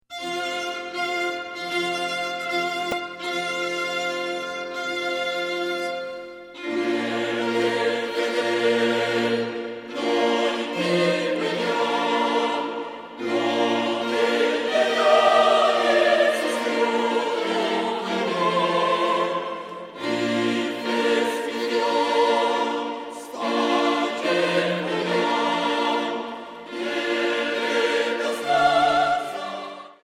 Otras fueron realizadas en "vivo" durante alguna de nuestras presentaciones.